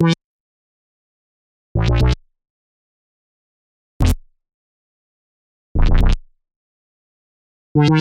合成器2
描述：合成器fl工作室
Tag: 120 bpm Trap Loops Synth Loops 1.35 MB wav Key : Unknown